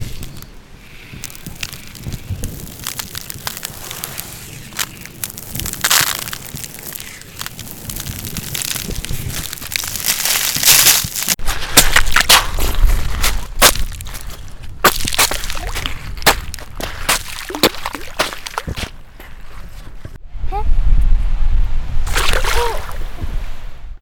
모래놀이.mp3